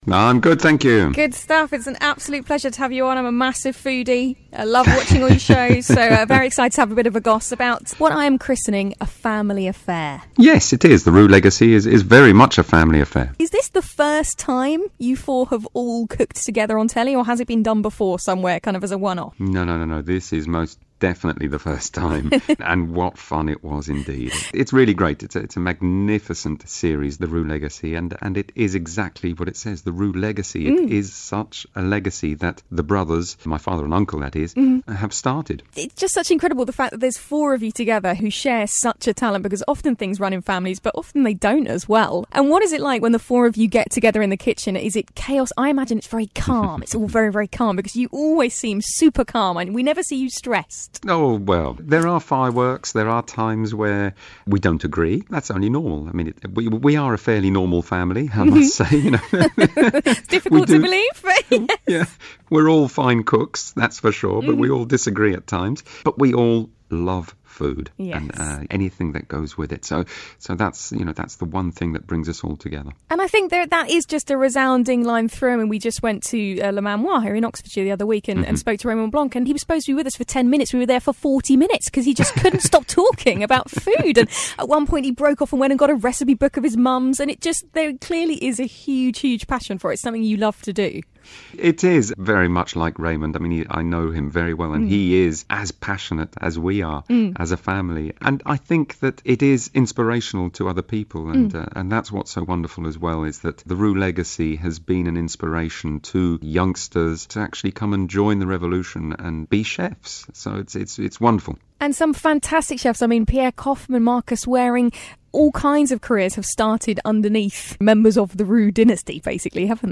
Glide's Morning Glory Interview Michel Roux Jr. Part 1